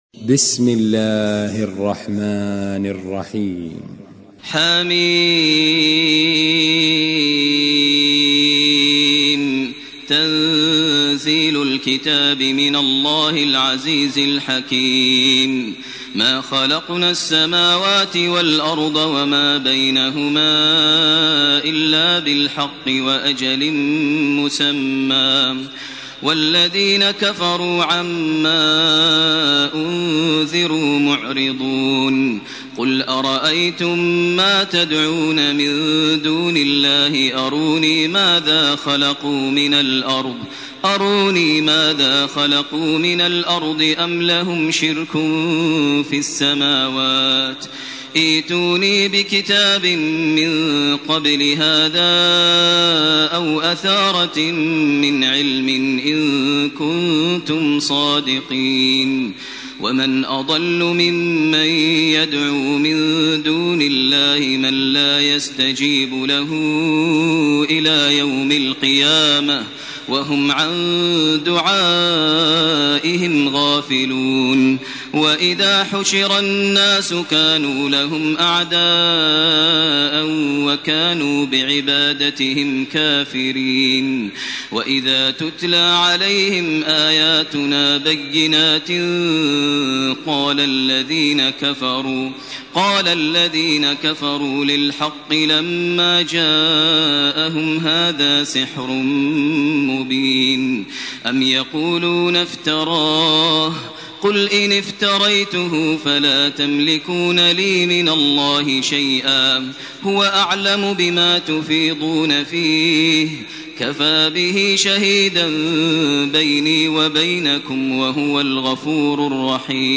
الاحقاف و الفتح من 1 -17 > تراويح ١٤٢٨ > التراويح - تلاوات ماهر المعيقلي